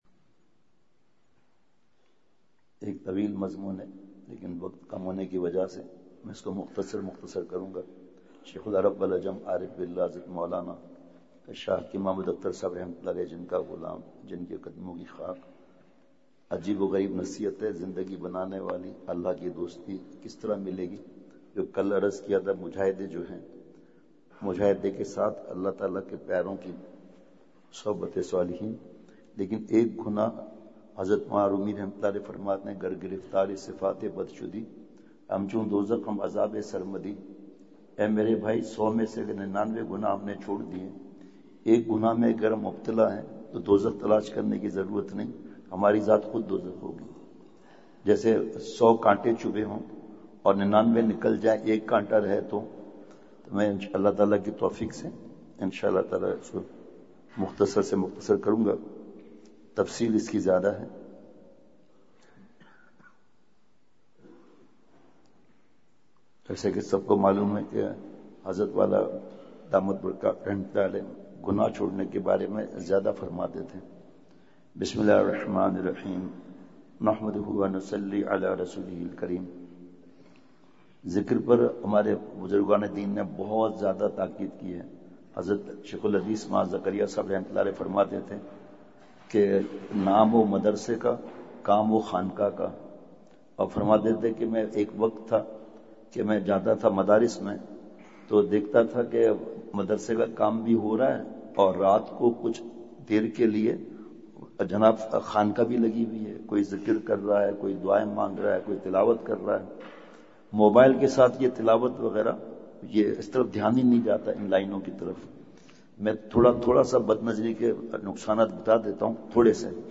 بمقام: جامعہ خیرالمدارس ملتان